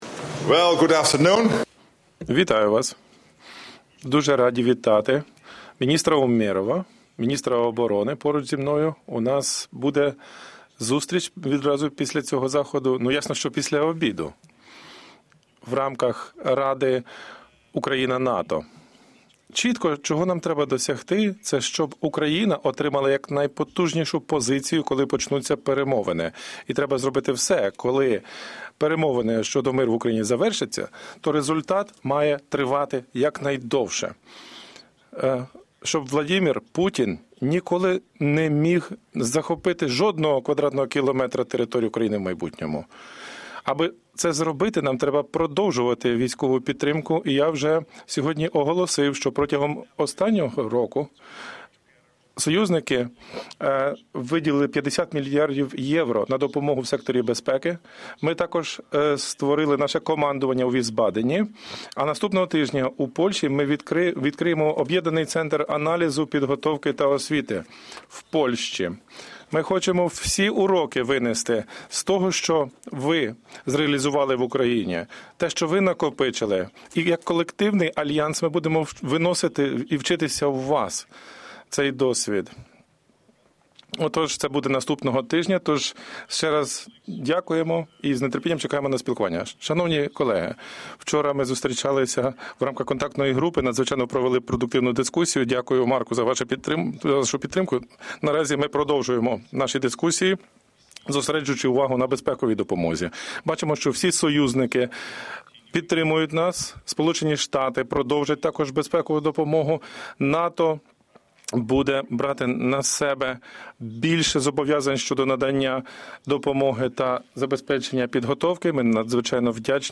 ENGLISH - Opening remarks by NATO Secretary General Mark Rutte at the North Atlantic Council in Defence Ministers Session